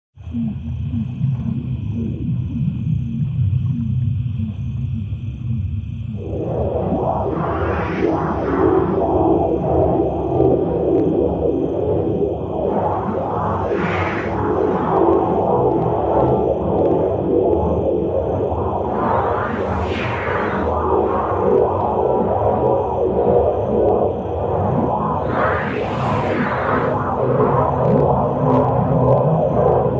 dark-noise-ringtone_14279.mp3